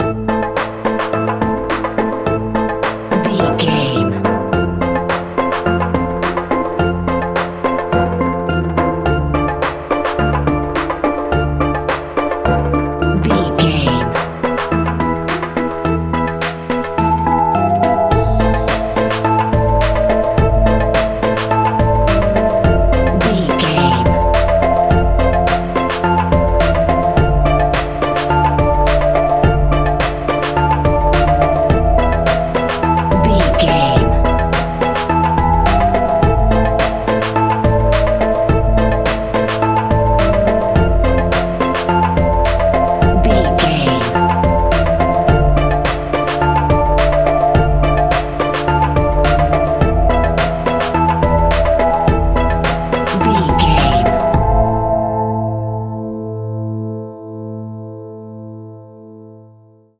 Aeolian/Minor
scary
ominous
dark
eerie
synthesiser
electric piano
strings
drums
percussion
spooky
horror music